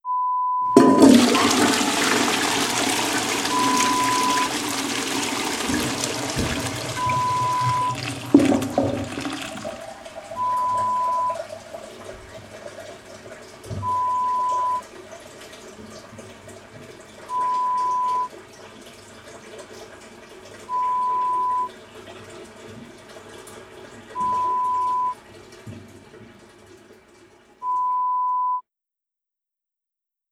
Descarga de banheiro = descarga de inodoro
Sonido de cisterna de inodoro al vaciarse. Acompaña a dicho sonido un pitido espaciado y constante no relacionado con dicha actividad
Sonidos: Acciones humanas